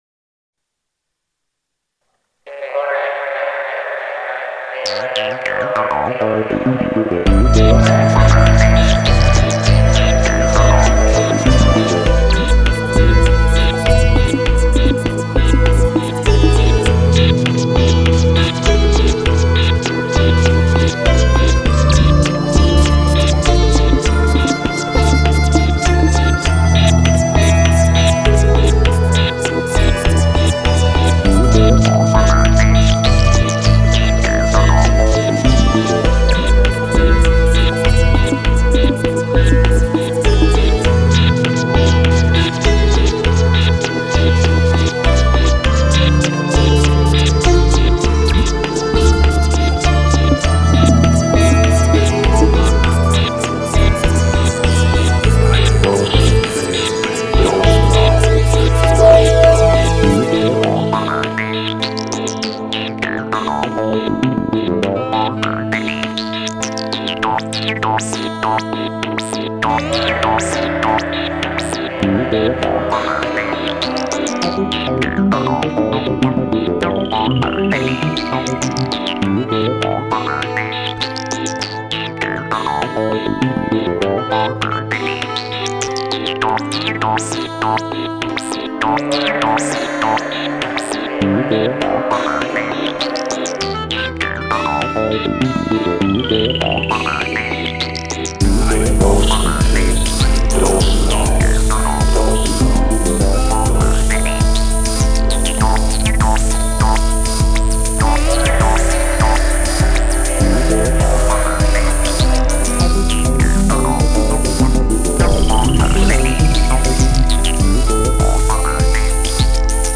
“Chill Out”音乐可称作“悠闲”、“舒服”的代名词，
具冷静、放松、平实的特点，是现在最时尚、最受各阶层乐迷
专辑中以电子音乐为主体穿插、融合了民族
音乐、古典、爵士等曲风各异的音乐类型。
蜜糖般的节奏给人一种醉人的感受，
这张专辑无论从编曲还是录音都达到了很高的水平。
缓慢铺陈的音乐调性之中，表达的是一种状态，慢慢地、
节奏平坦少有起浮。